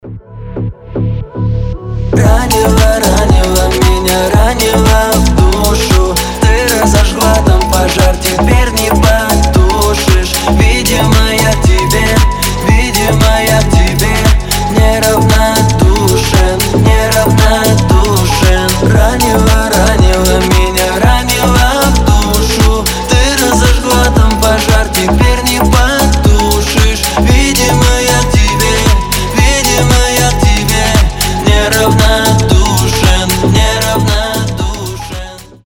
• Качество: 320, Stereo
мужской голос
deep house
Club House